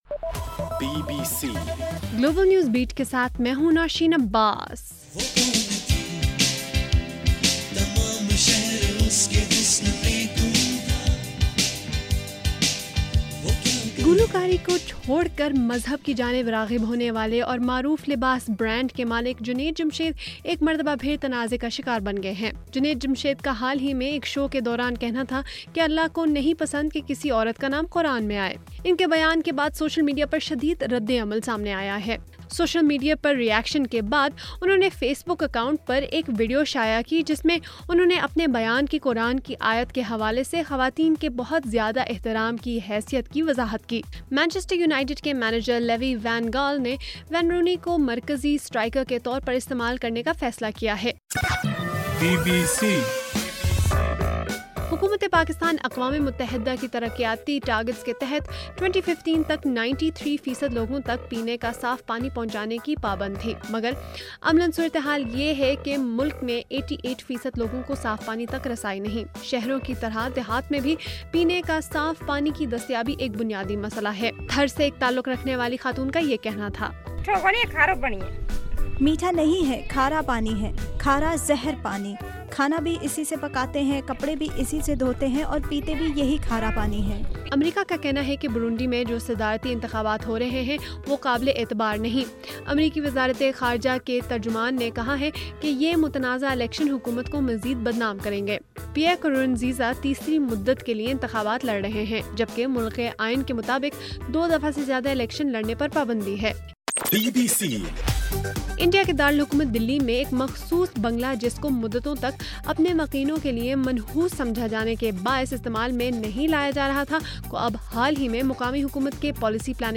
جولائی 21: رات 12 بجے کا گلوبل نیوز بیٹ بُلیٹن